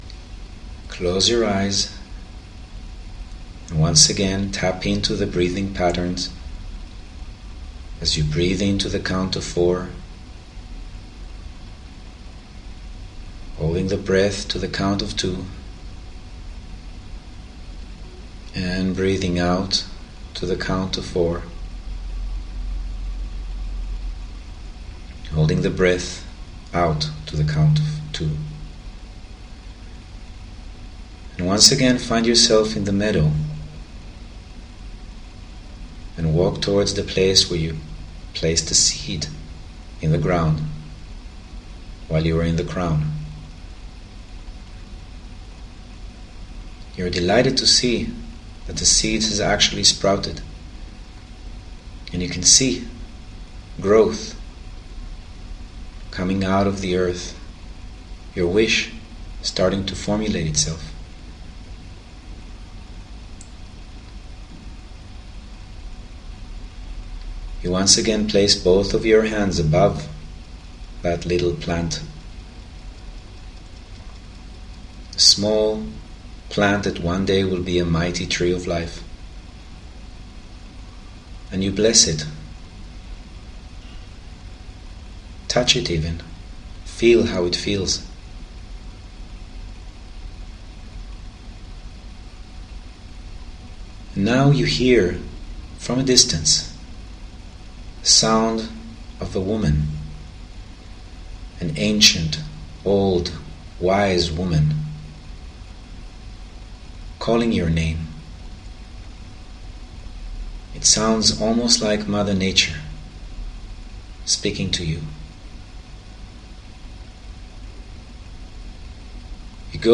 Understanding - Exercise 1, Day 1 Listen to the Meditation on Understanding. This meditation is designed to help you connect to the energy of the third sphere.